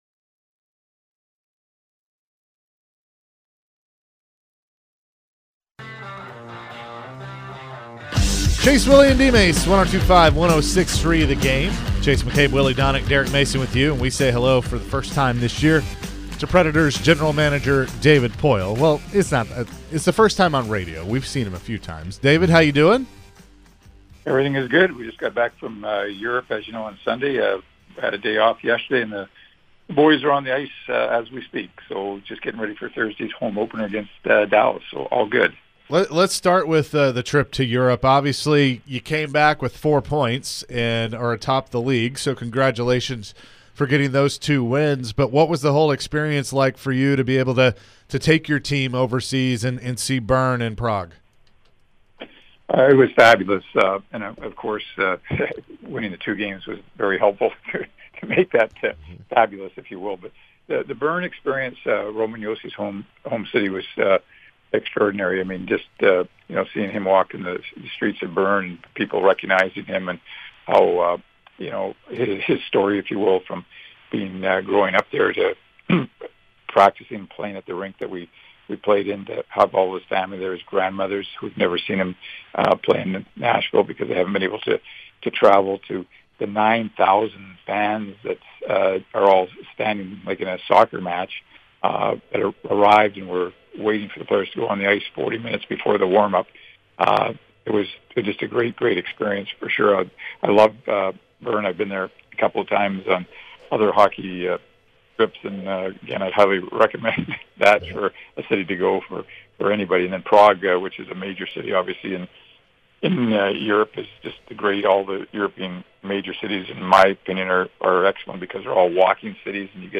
David Poile Full Interview (10-11-22)